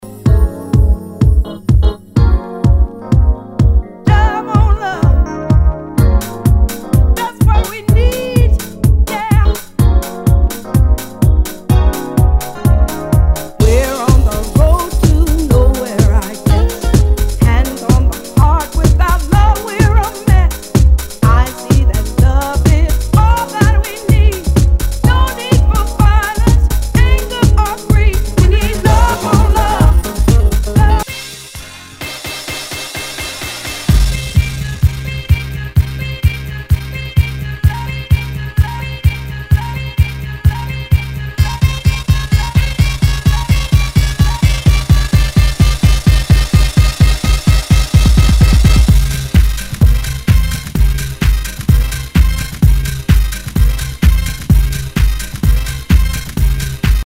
HOUSE/TECHNO/ELECTRO
ナイス！ヴォーカル・ハウス ！